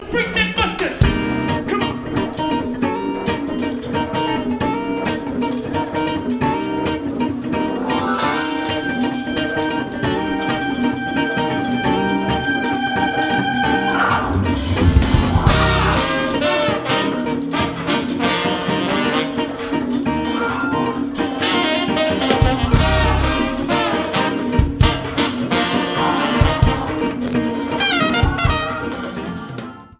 New Orleans, LA - April 25, 2014
Congo Square
Funk
R&B